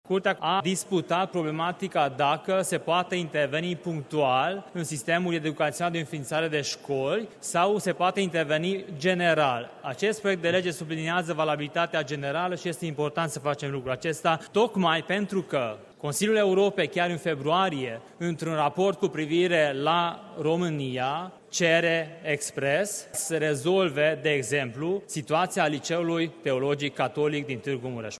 În replică, deputatul Atilla Korodi a apărat amendamentul UDMR: